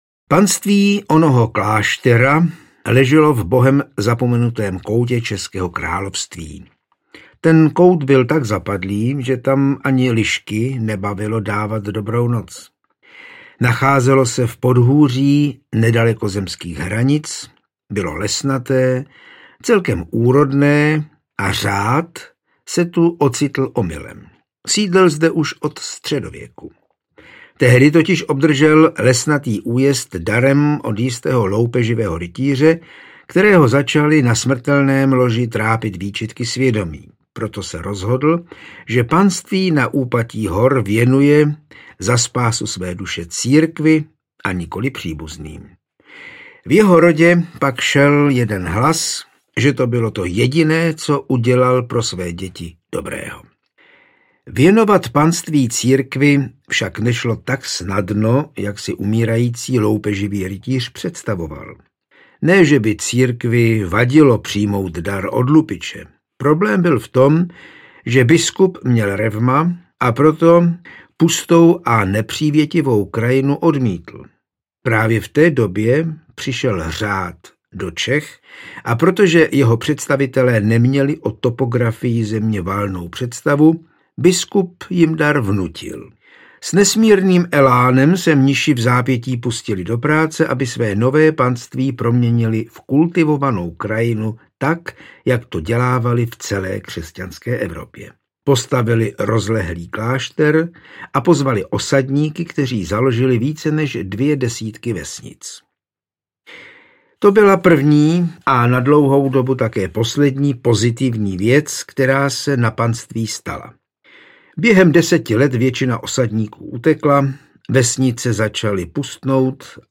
Klášterní madrigal - Historie jednoho panství audiokniha
Ukázka z knihy